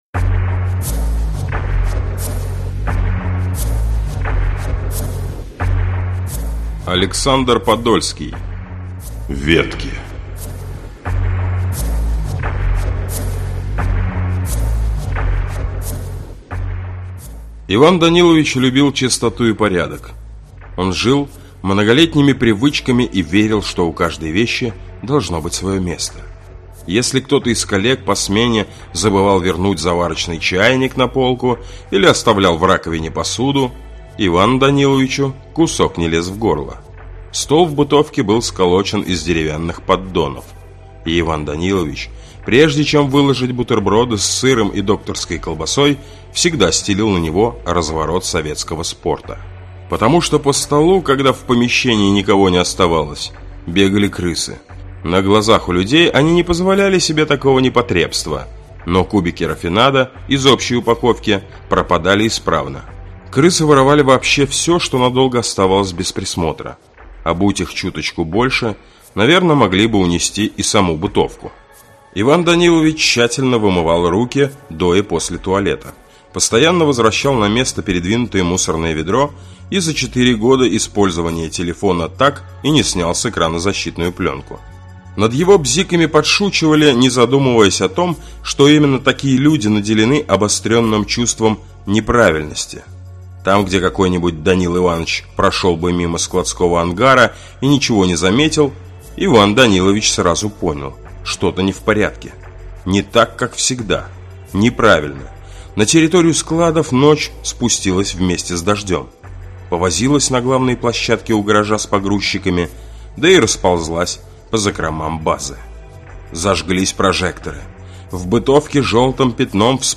Аудиокнига Ненужные | Библиотека аудиокниг
Прослушать и бесплатно скачать фрагмент аудиокниги